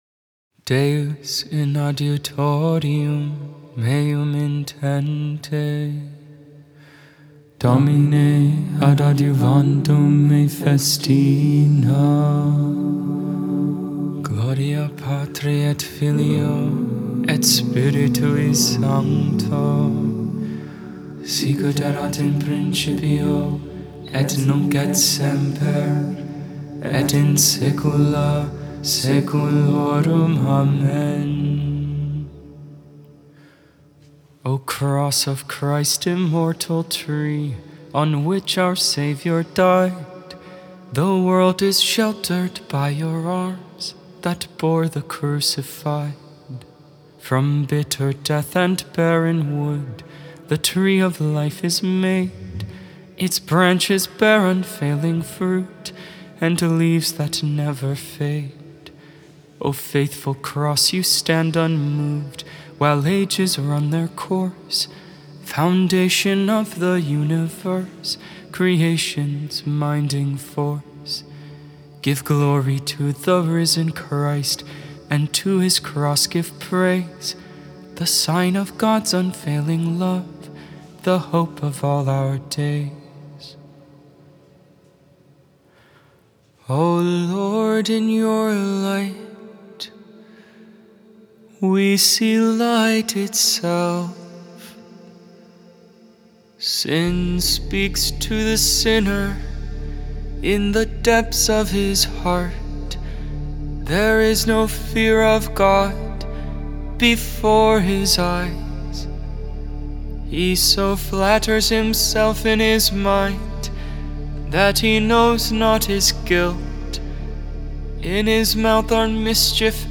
Lauds, Morning Prayer for the 1st Wednesday in Lent, March 1st, 2023.
Gregorian